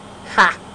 Ha Sound Effect Download
Ha Sound Effect